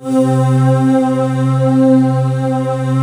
Index of /90_sSampleCDs/USB Soundscan vol.28 - Choir Acoustic & Synth [AKAI] 1CD/Partition D/23-SOMEVOICE